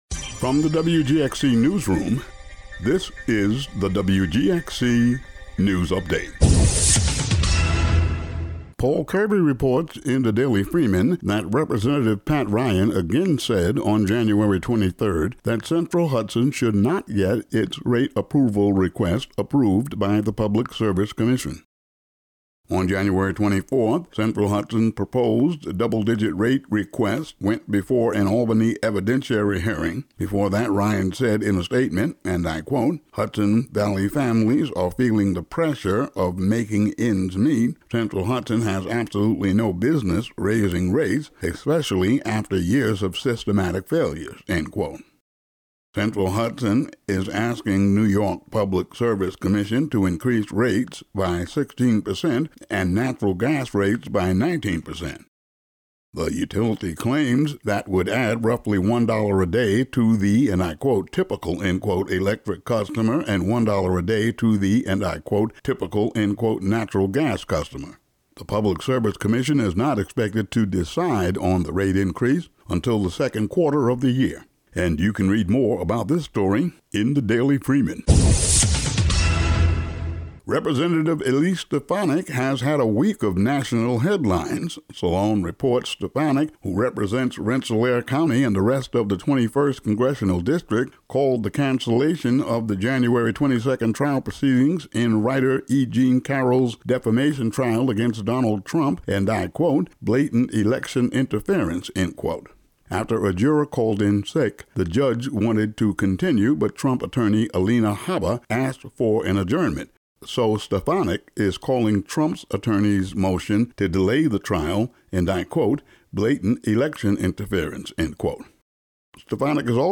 Today's daily local audio news update.